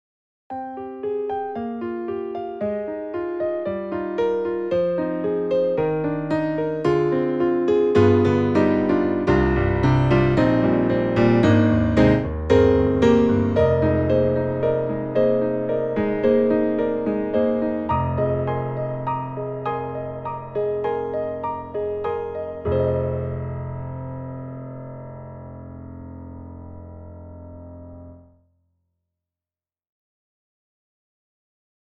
特集：徹底比較！DTM音源ピアノ音色聴き比べ - S-studio2
Studio
e-instruments_Session Keys Grand S_Studio.mp3